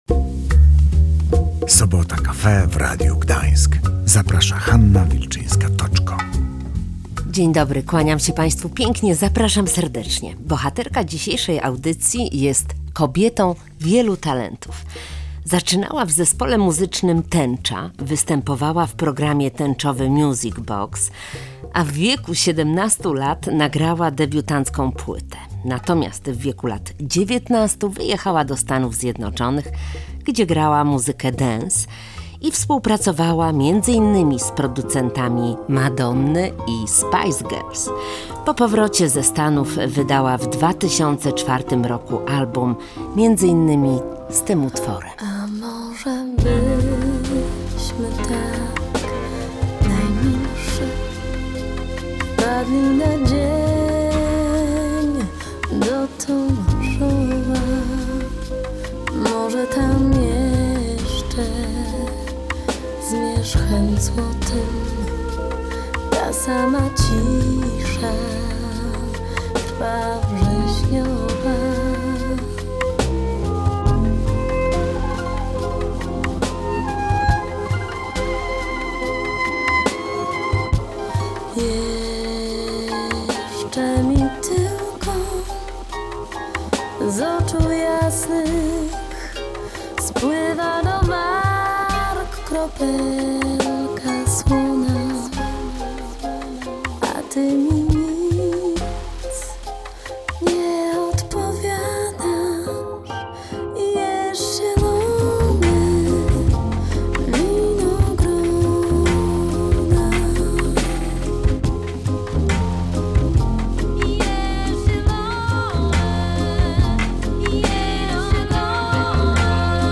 „Początek nocy” to najnowszy album Marii Sadowskiej. Wokalistka i reżyserka opowiada o swoim dziele